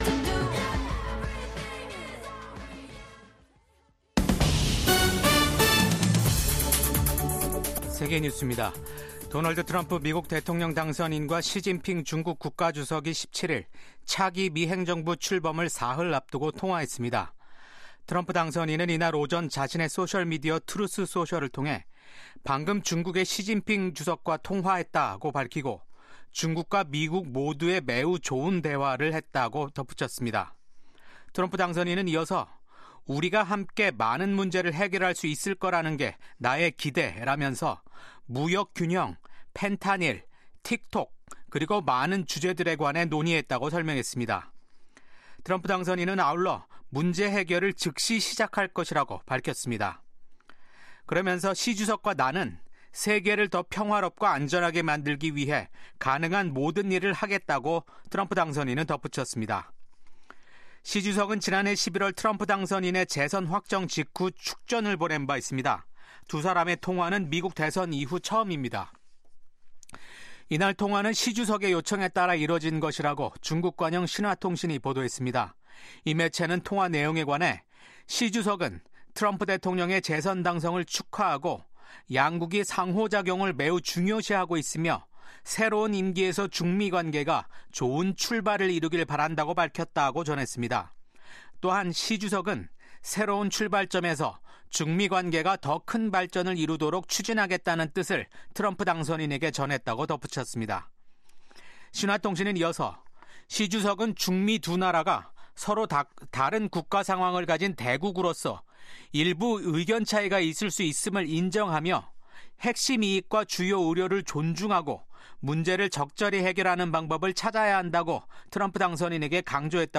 VOA 한국어 아침 뉴스 프로그램 '워싱턴 뉴스 광장'입니다. 트럼프 2기 행정부 출범과 윤 대통령 탄핵 등 한국의 정치적 혼란으로 인해 단기적으로 미한동맹 간 정치, 경제 모두에서 불확실성이 커질 것으로 보입니다.